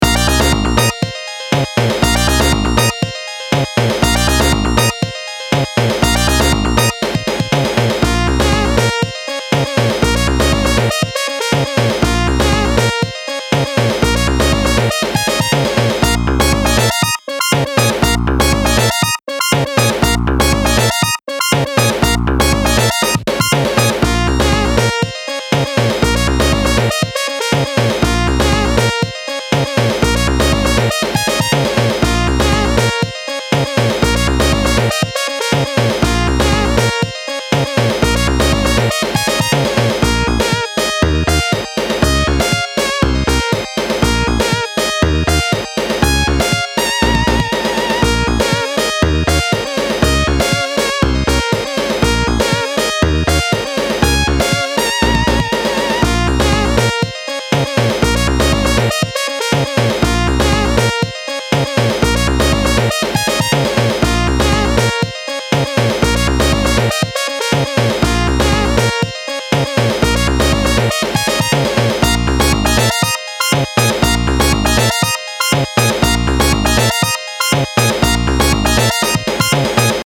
Style Style Chiptune
Mood Mood Bright, Cool
Featured Featured Bass, Drums, Synth
BPM BPM 120